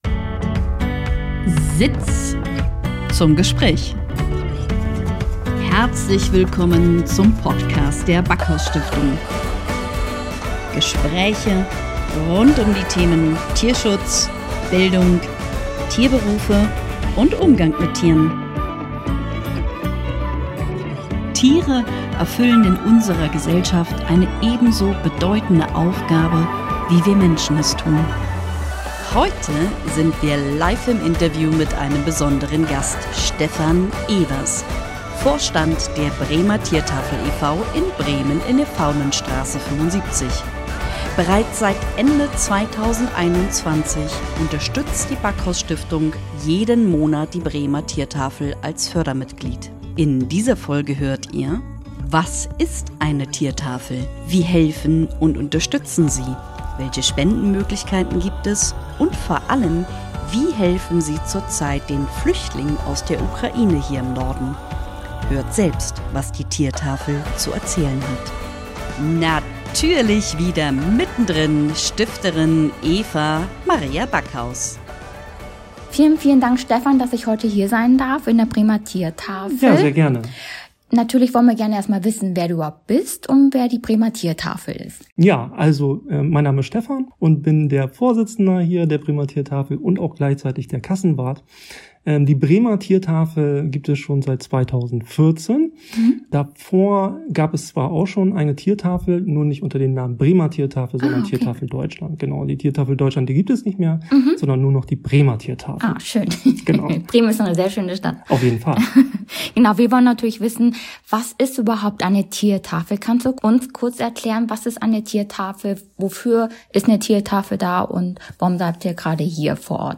In dieser Folge haben wir im Interview